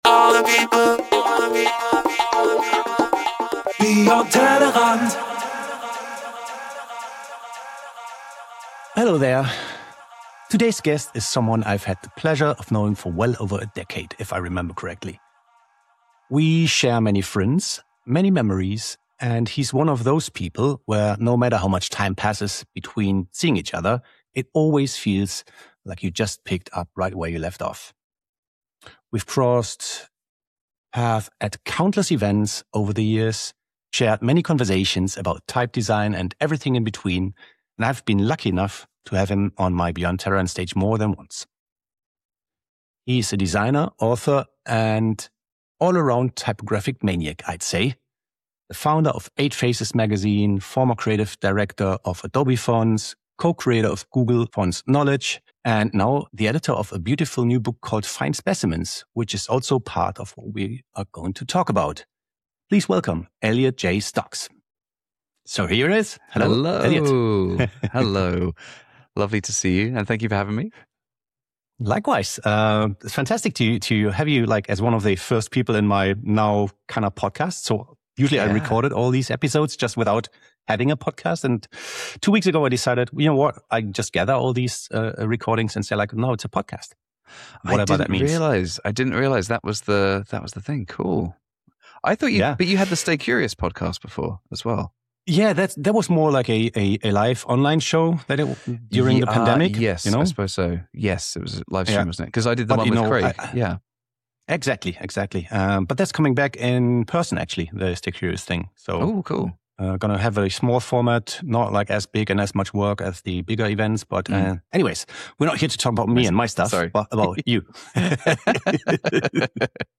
Podcast (Audio-Only) Version